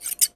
Tijera recortando un papel 1
tijera
Sonidos: Acciones humanas
Sonidos: Oficina